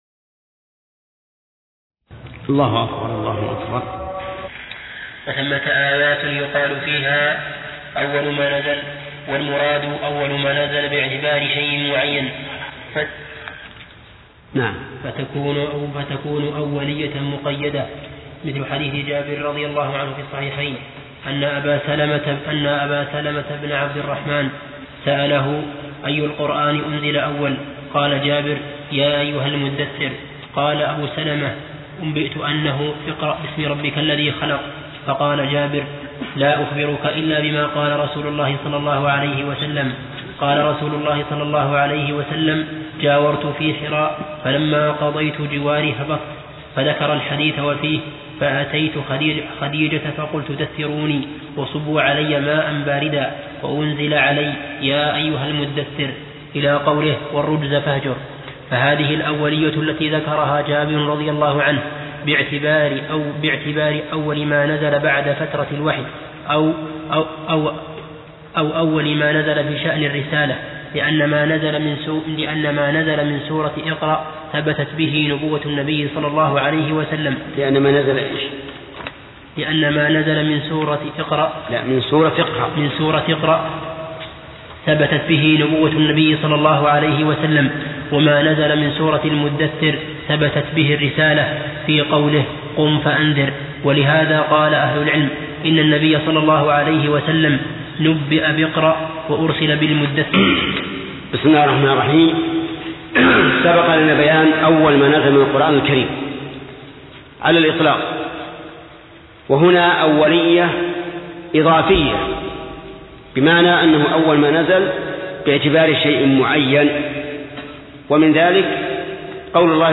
درس (4) : من صفحة: (79)، قوله: (وثمت آيات يقال فيها ..)، إلى صفحة: (100)، قوله: (فالمهم أن النبي ترك إقامة الحد على هذا الخبيث لأسباب...)